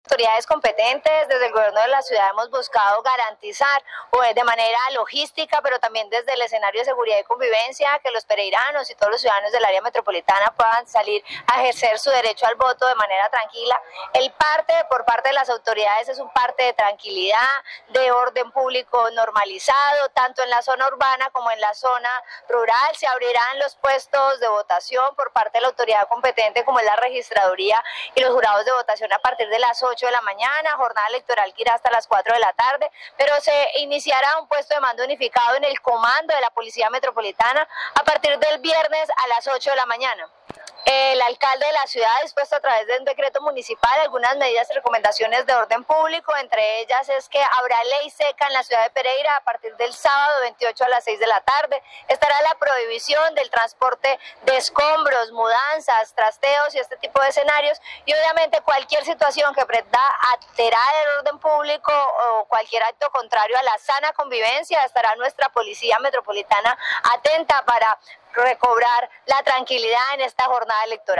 KAREN_ZAPE_SECRETARIA_DE_GOBIERNO.02.mp3